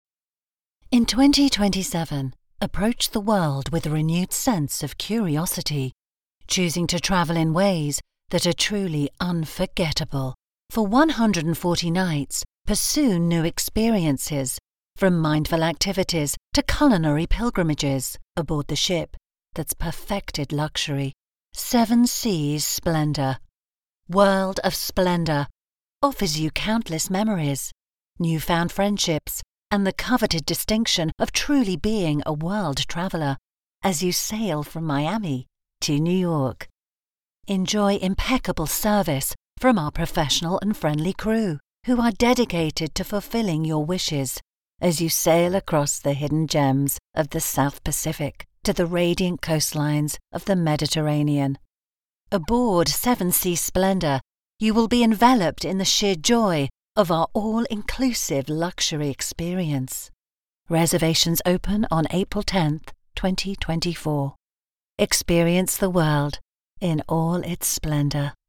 Corporate & Industrial Voice Overs
Adult (30-50)